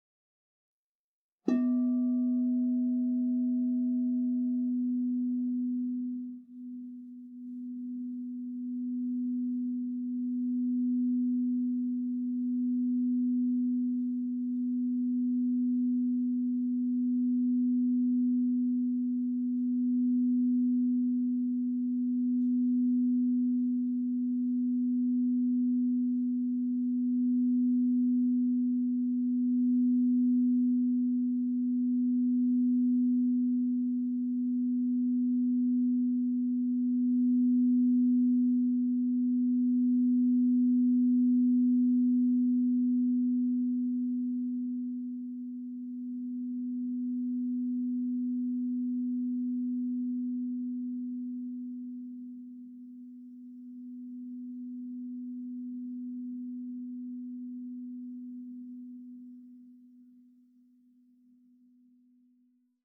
Meinl Sonic Energy 12" white-frosted Crystal Singing Bowl B3, 440 Hz, Crown Chakra (CSBM12B3)
The white-frosted Meinl Sonic Energy Crystal Singing Bowls made of high-purity quartz create a very pleasant aura with their sound and design.